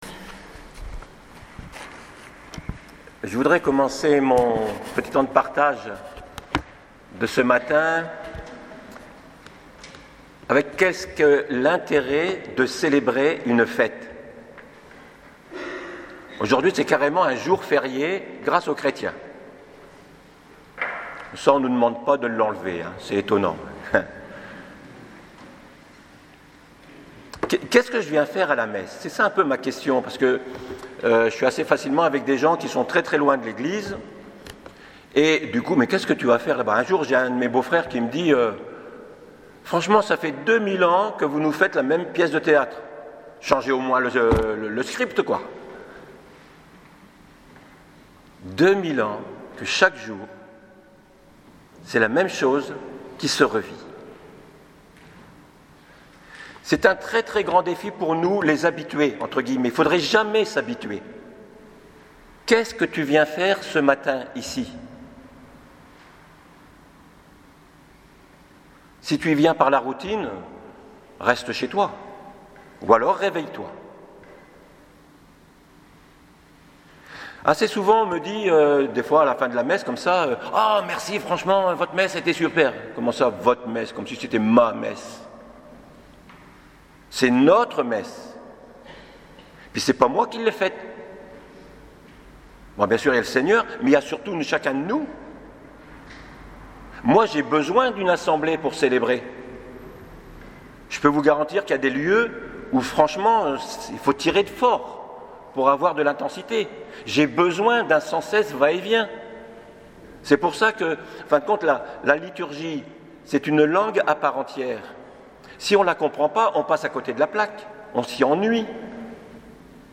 la version audio de l’homélie est ici: => fête et assomption